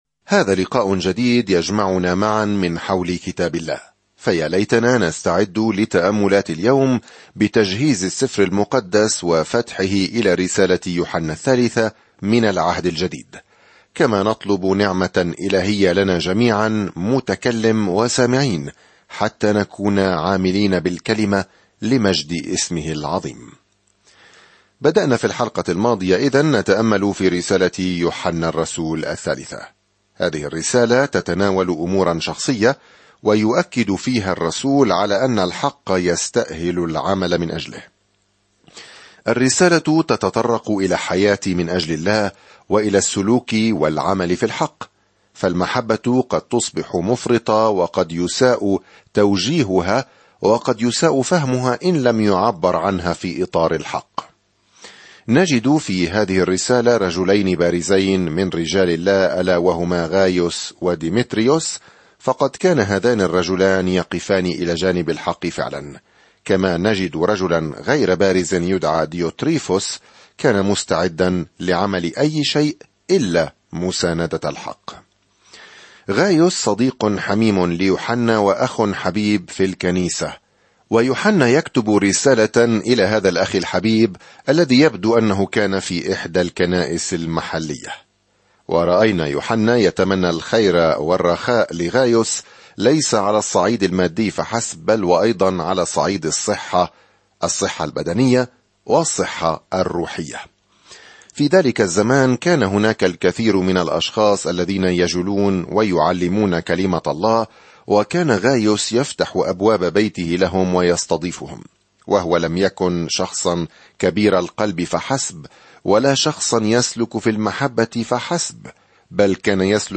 الكلمة يُوحَنَّا ٱلثَّالِثَةُ 4:1-8 يوم 1 ابدأ هذه الخطة يوم 3 عن هذه الخطة اسلكوا بالحق وأحبوا بعضكم بعضًا – هذه هي رسالة الرسالة الثالثة من يوحنا حول كيفية دعم الحق والتعامل مع المعلمين الكذبة. سافر يوميًا عبر رسالة يوحنا الثالثة وأنت تستمع إلى الدراسة الصوتية وتقرأ آيات مختارة من كلمة الله.